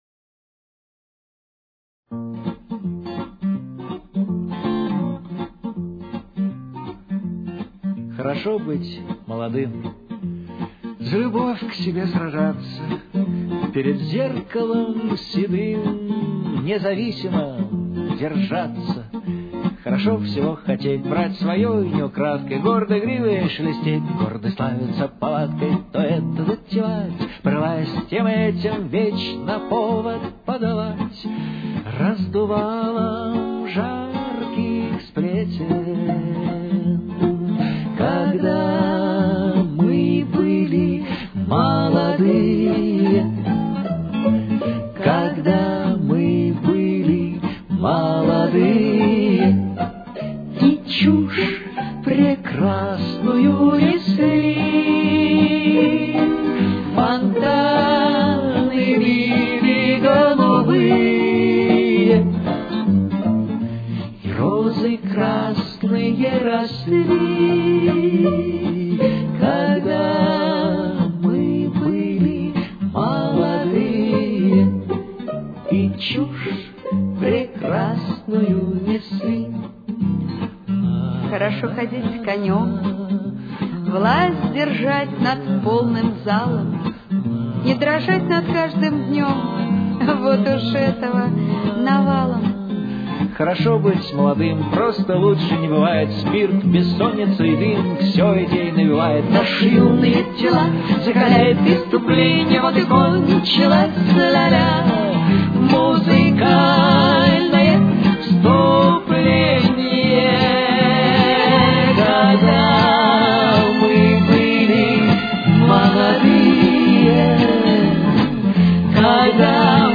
Темп: 155.